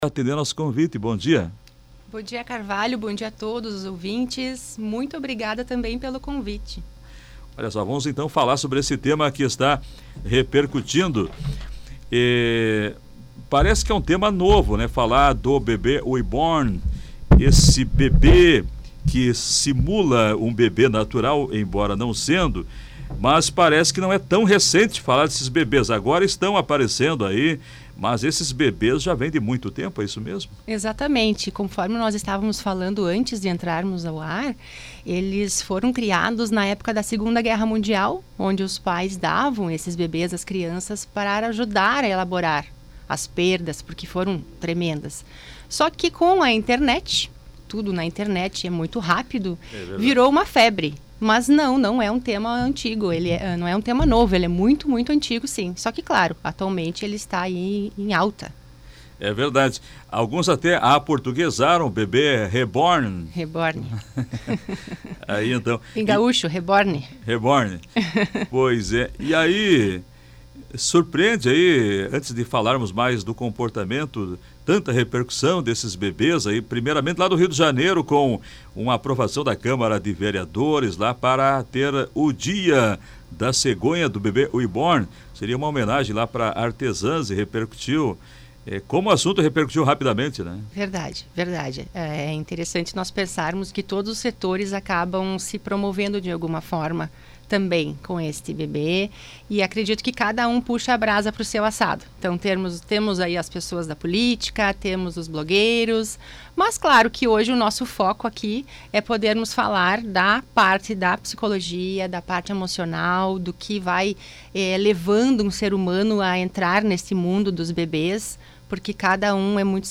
Esse foi o tema da entrevista no programa Comando Popular.